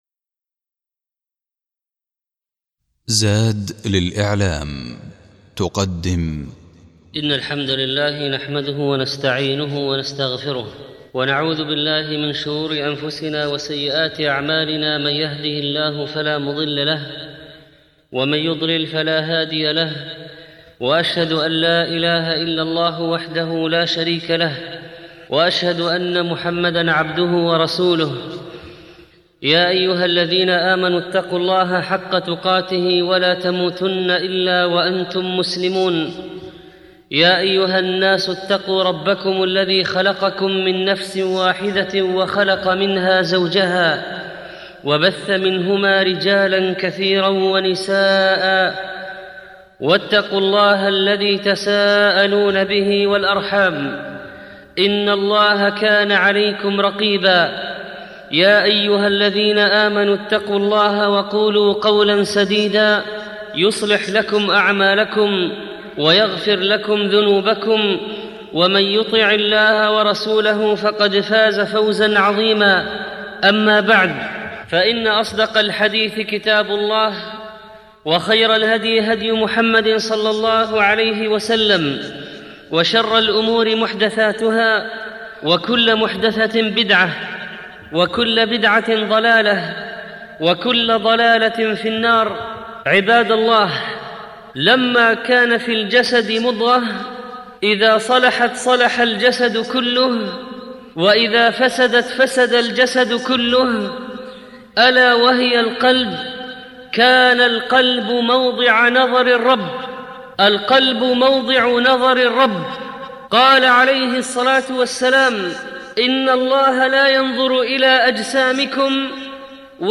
الخطبة الأولى أهمية صلاح القلب نماذج من السلف في صلاح القلب